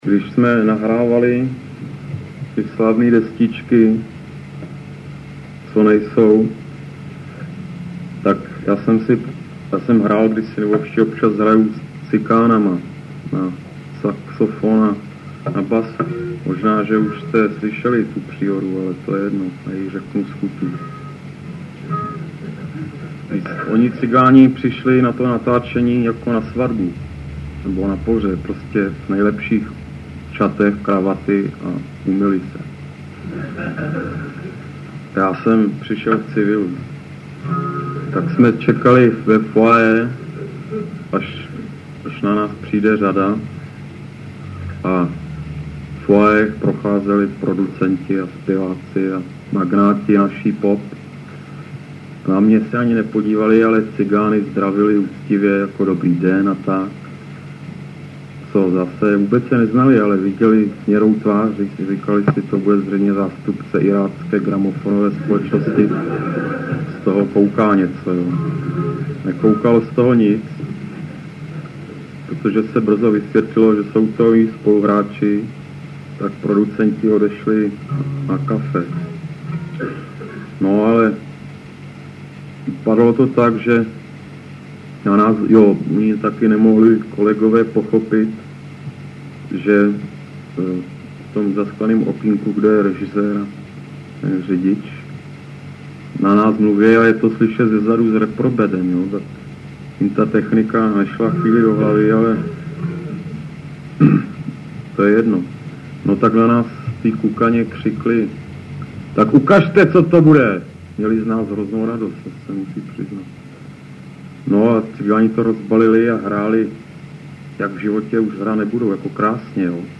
krátkou nahrávku z koncertu